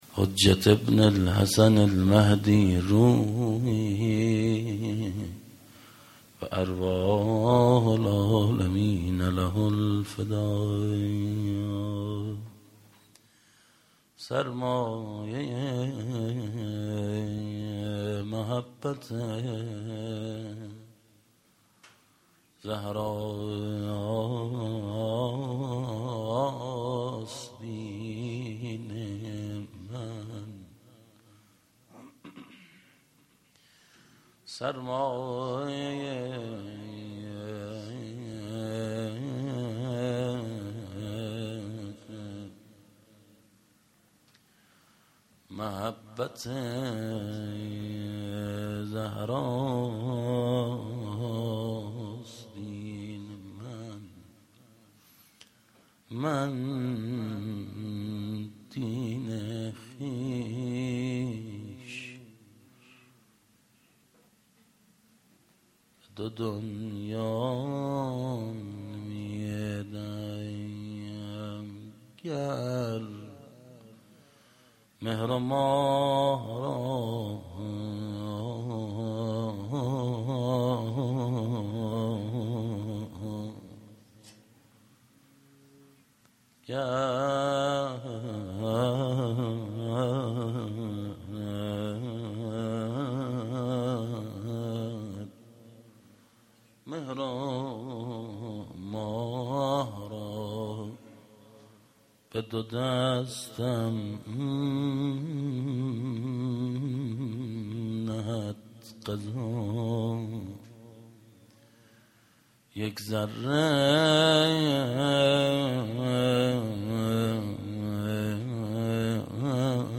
sokhanrani.mp3